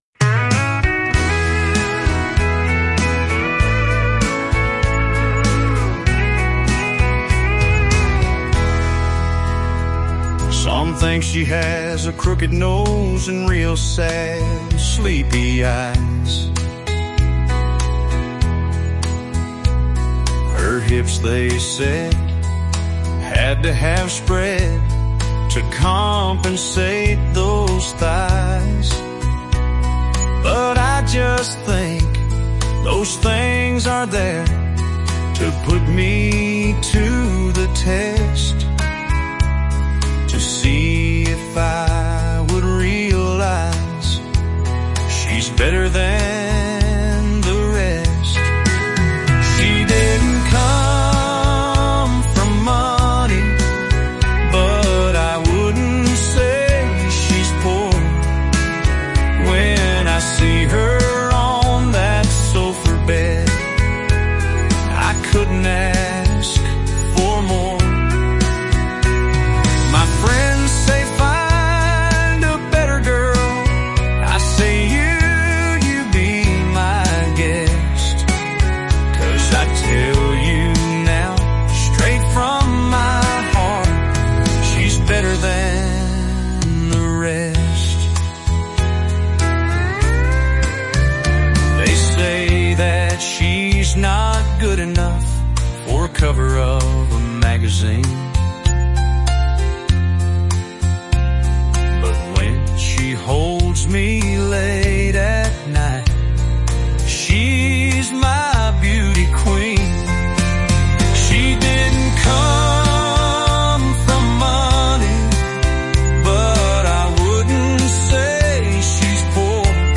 That chorus is pure gold.
This is great.....You always come up with these catchy tunes....cheers